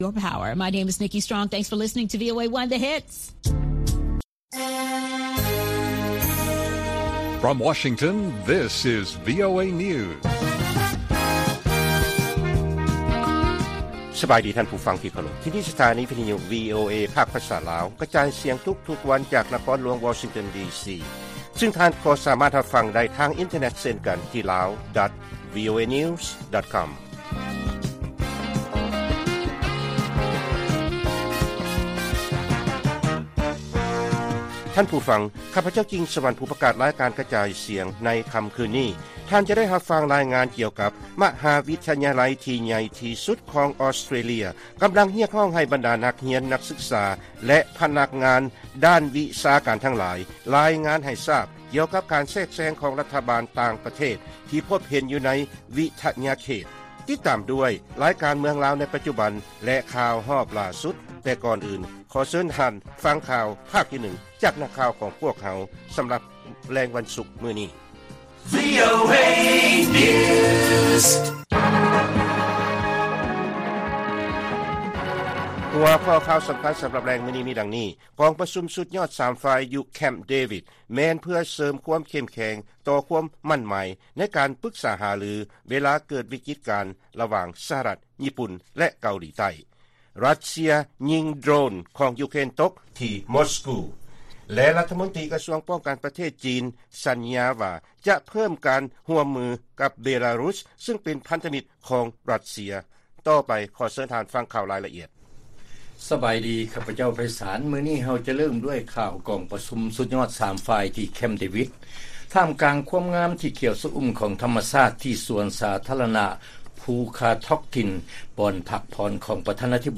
ວີໂອເອພາກພາສາລາວ ກະຈາຍສຽງທຸກໆວັນ, ຫົວຂໍ້ຂ່າວສໍາຄັນໃນມື້ນີ້ມີ: 1. ກອງປະຊຸມສຸດຍອດສາມຝ່າຍ ທີ່ແຄ້ມເດວິດ, 2. ຣັດເຊຍ ຍິງເຮືອບິນບໍ່ມີຄົນຂັບ ຫຼືໂດຣນຂອງຢູເຄຣນຕົກ, ແລະ 3. ລັດຖະມົນຕີປ້ອງກັນປະເທດຂອງ ຈີນ ສັນຍາຈະຂະຫຍາຍການຮ່ວມມື ກັບ ເບລາຣຸສ.